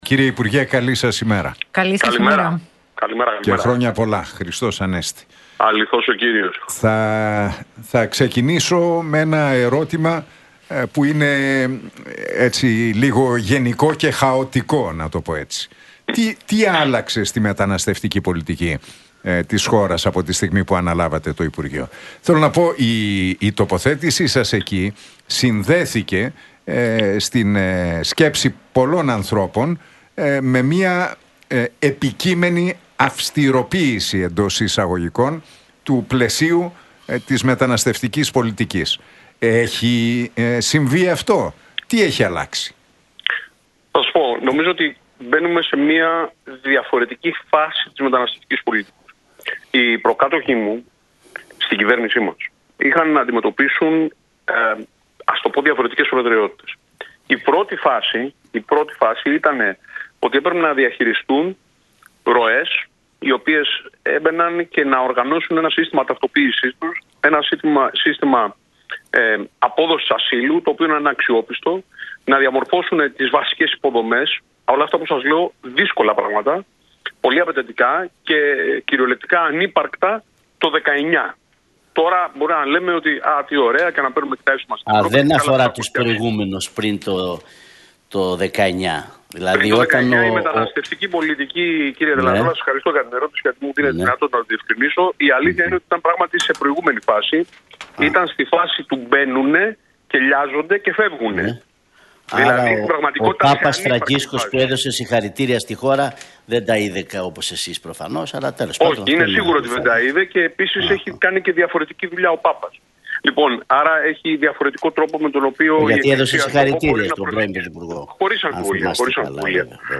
Βορίδης στον Realfm 97,8: Στο επόμενο Υπουργικό Συμβούλιο θα φέρω αυστηροποίηση του νόμου για τις επιστροφές μεταναστών